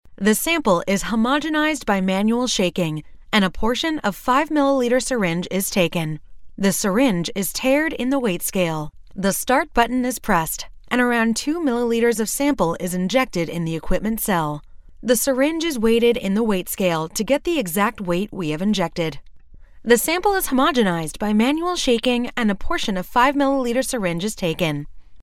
locutora norteamericana, american english voice over